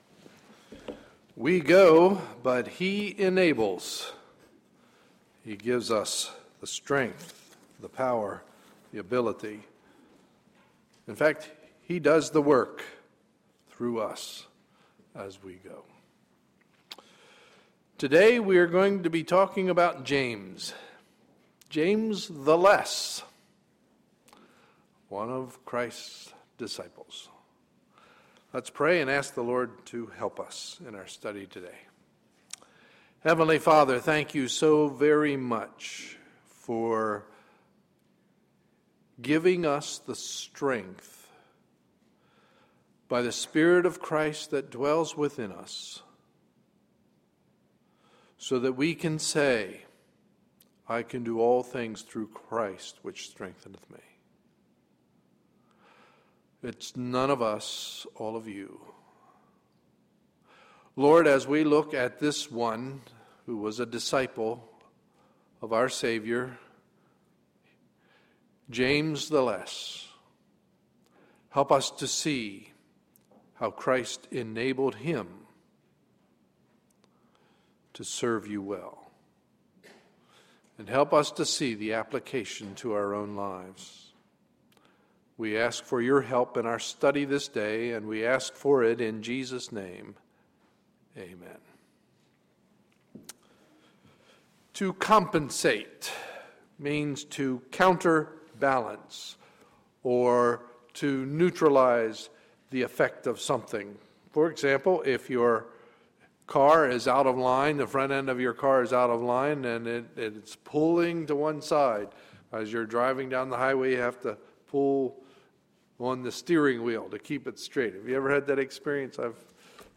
Sunday, September 2, 2012 – Morning Message